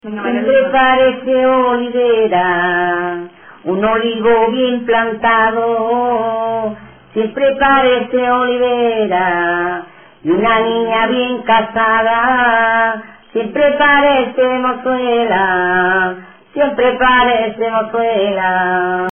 Materia / geográfico / evento: Fandanguillos Icono con lupa
Arenas del Rey (Granada) Icono con lupa
Secciones - Biblioteca de Voces - Cultura oral